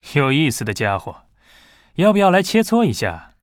文件 文件历史 文件用途 全域文件用途 Bhan_fw_01.ogg （Ogg Vorbis声音文件，长度3.4秒，99 kbps，文件大小：42 KB） 源地址:游戏中的语音 文件历史 点击某个日期/时间查看对应时刻的文件。